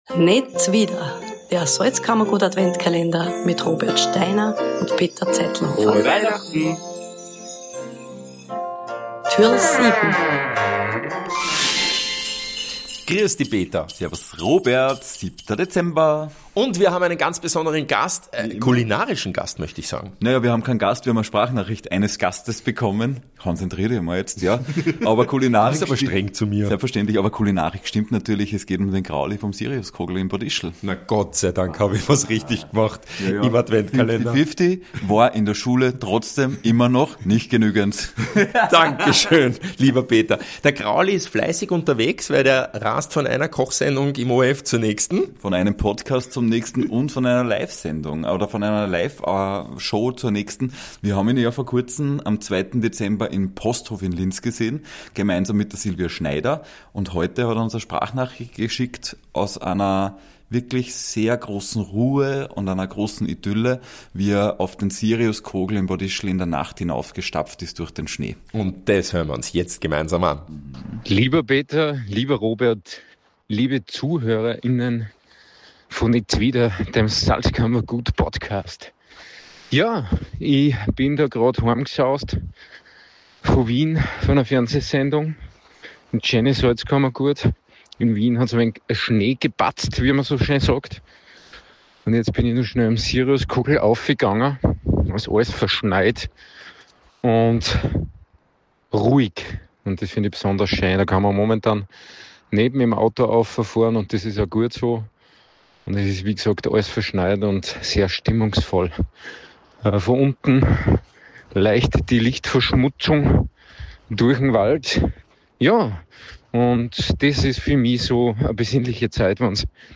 Wer kommt denn da durch den Schnee gestapft.